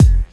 Index of /90_sSampleCDs/Best Service ProSamples vol.15 - Dance Drums [AKAI] 1CD/Partition C/KIT 07-12